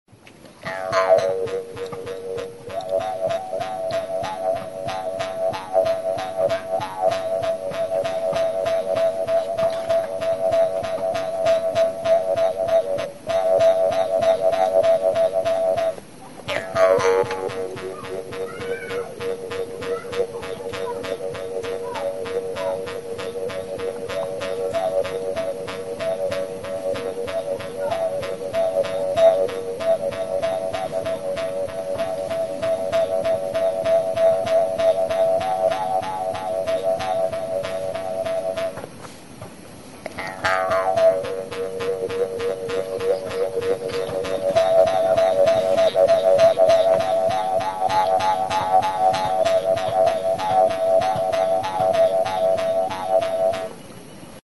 Idiófonos -> Punteados / flexible -> Sin caja de resonancia
SUSAP; Jew's harp
Puntako bi aldeak loturik daude eta atzeko muturrean duen sokatxo gorriarekin astintzen da mihi hori; horrela hotsa lortzen da.
CAÑA; BAMBÚ